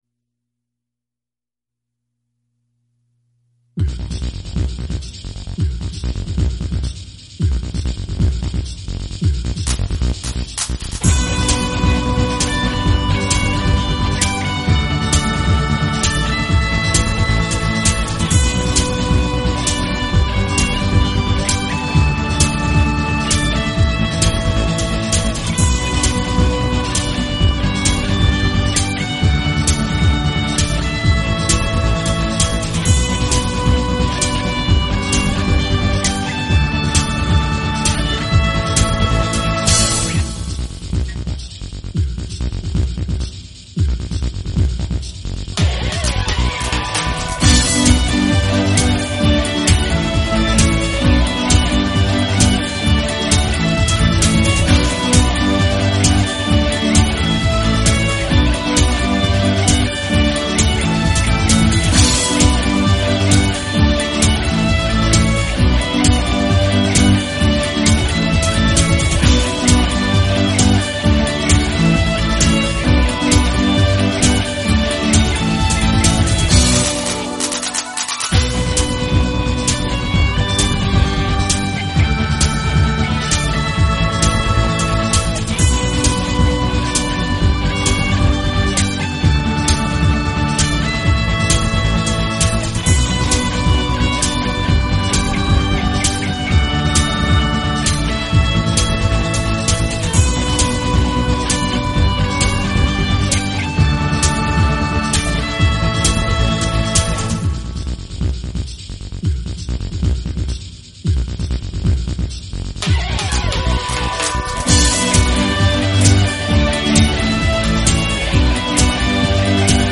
Keyboard,E.guitar
最初はトランス系ヒーリングみたいな感じに仕上ようと思ったのですが、結構激しくなってしまいました。